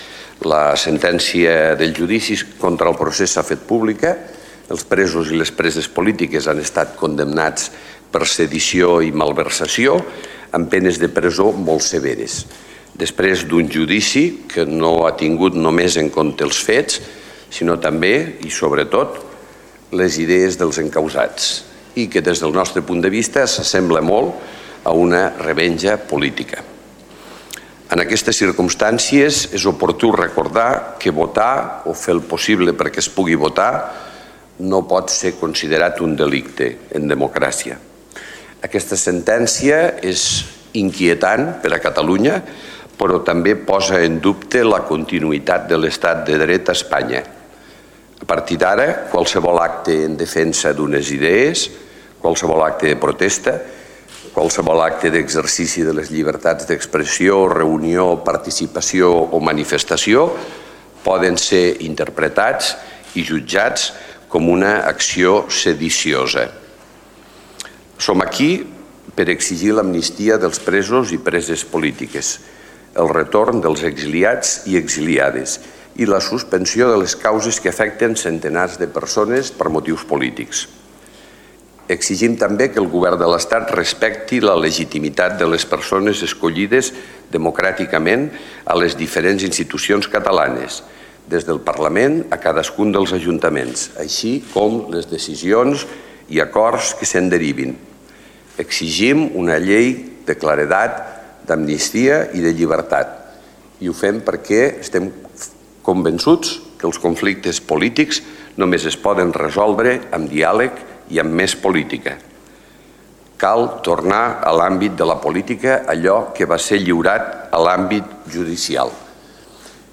audio-de-la-declaracio-de-lalcalde